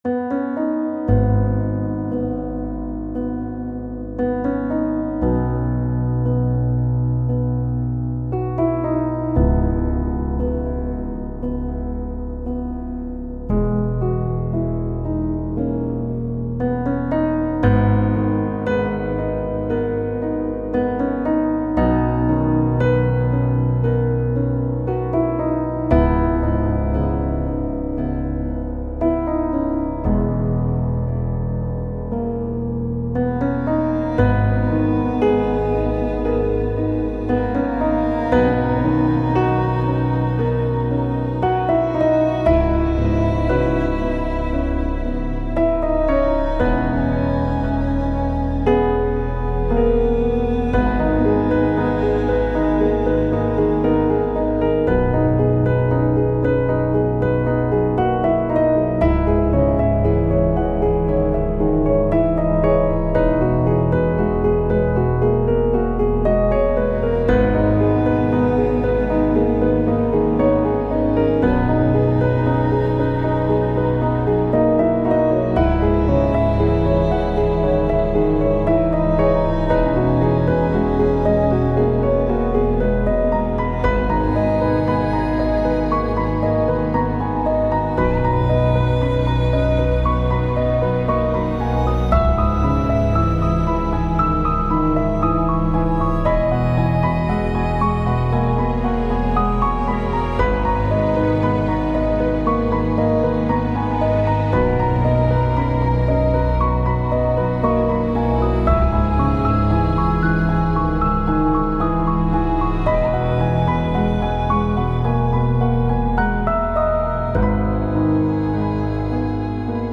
عصر جدید , موسیقی بی کلام
موسیقی بی کلام احساسی